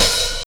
• Open High-Hat A Key 09.wav
Royality free open hi hat sound tuned to the A note. Loudest frequency: 4685Hz
open-high-hat-a-key-09-YTw.wav